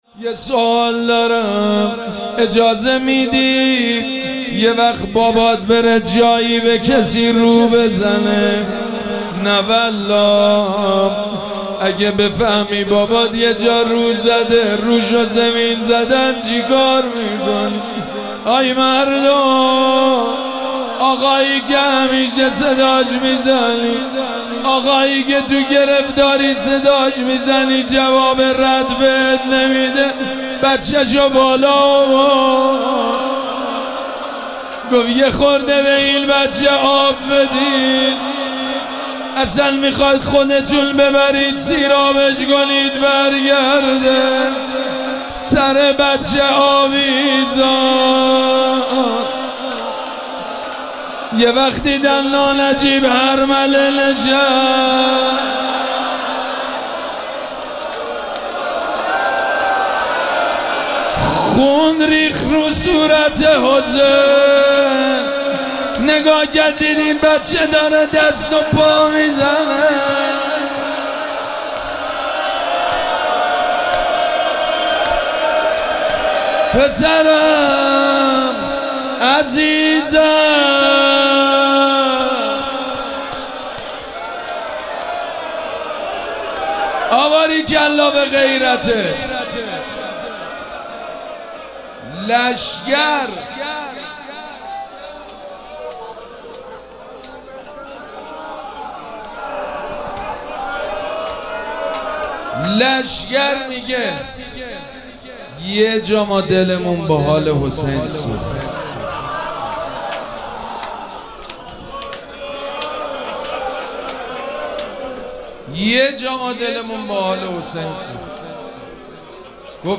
مراسم عزاداری شب هفتم محرم الحرام ۱۴۴۷
روضه
مداح